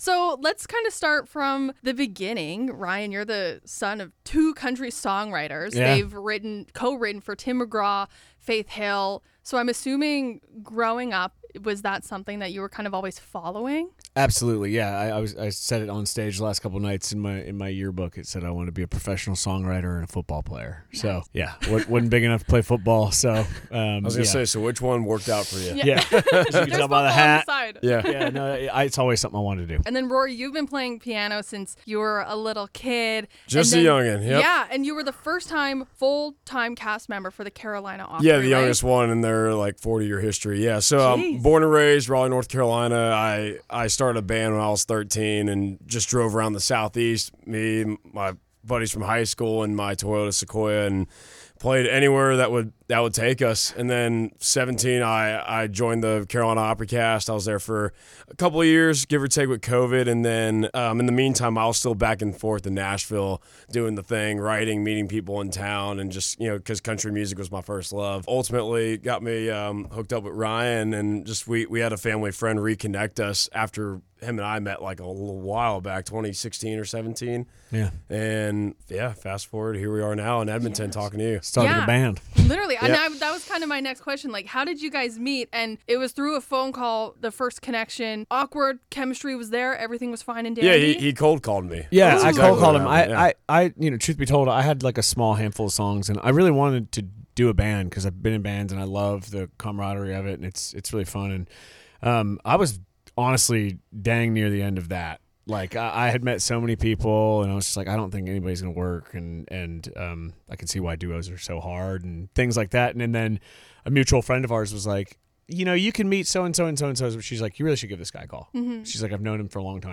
Nashville duo